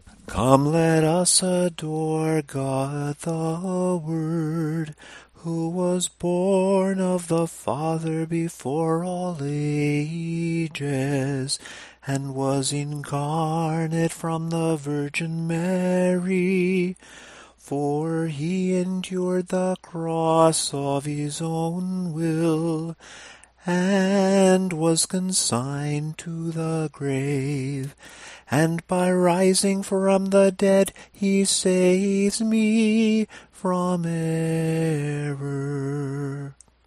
The melody consists of four parts, which we call A, B, C, and F (meaning final).
Tone_2_samohlasen_Sunday_sticheron_10.mp3